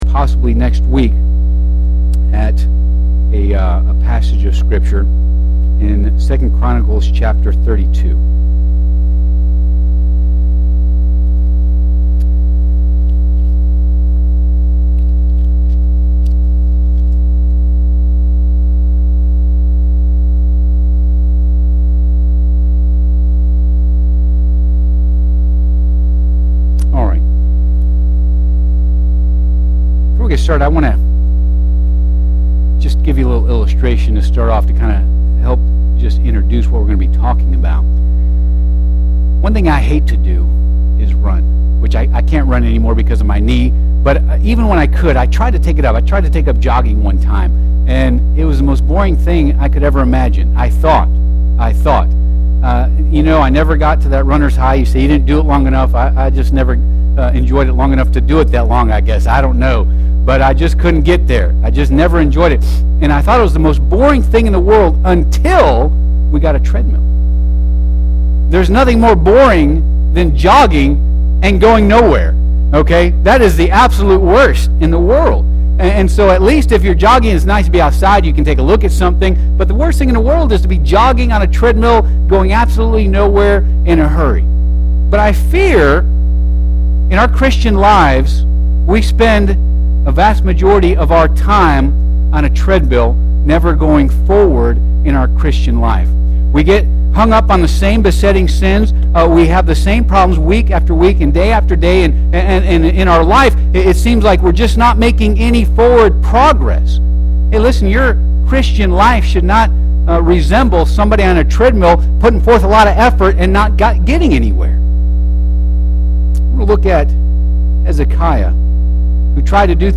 Bible Text: 2 Chronicles 32 | Preacher